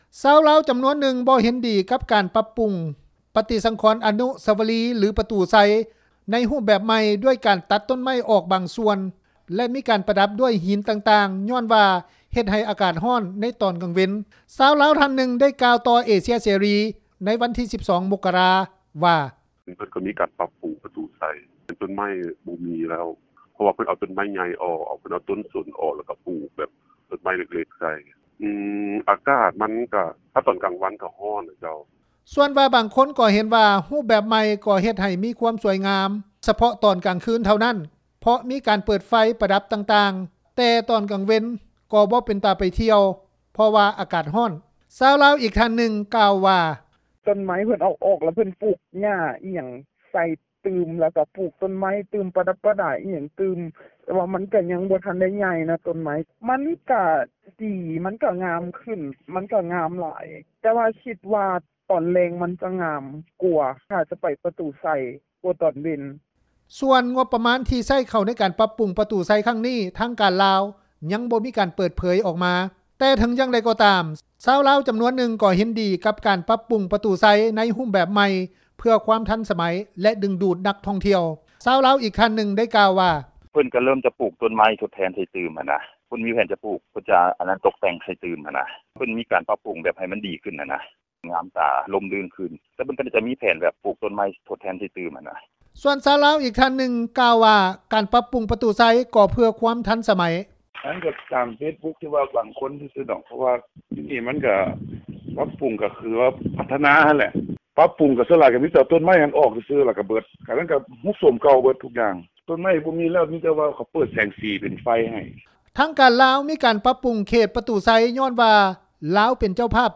ຊາວລາວ ທ່ານນຶ່ງ ໄດ້ກ່າວຕໍ່ວິທຍຸ ເອເຊັຽເສຣີ ໃນວັນທີ 12 ມົກກະຣາ ວ່າ: